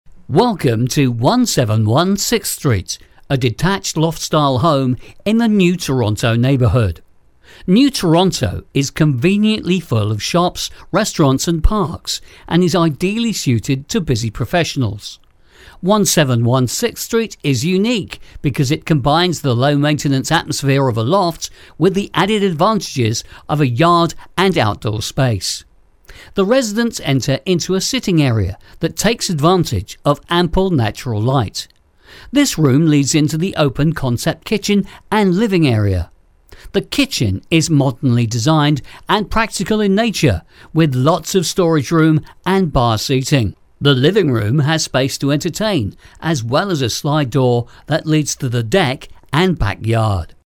british,voice,over,ISDN,studio,cockney,real, sounding,
cockney
Sprechprobe: eLearning (Muttersprache):